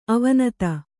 ♪ avanata